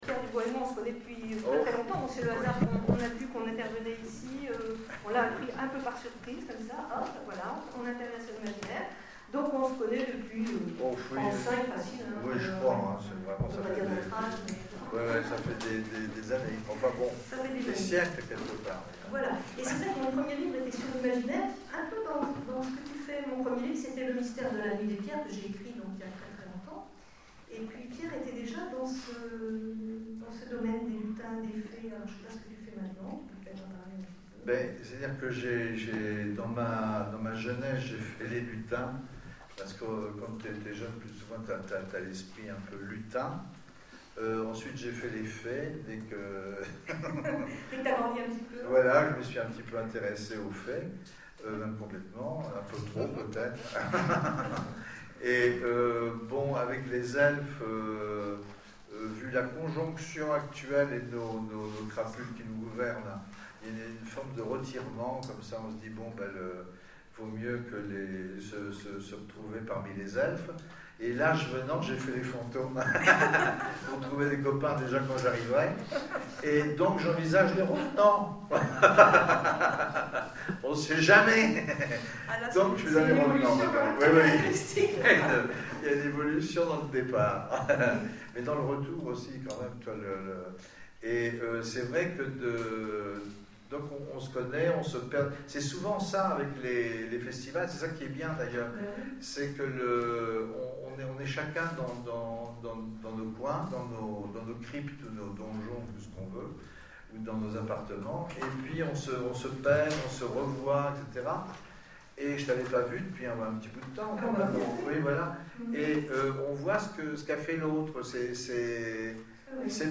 Etonnants Voyageurs 2013 : Conférence Fantômes et Farfafouilles